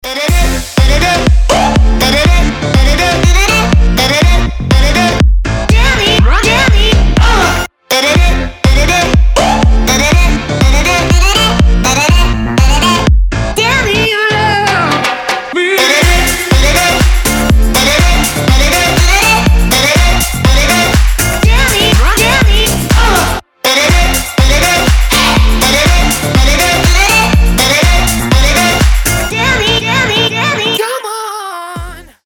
dance
Electronic
бодрые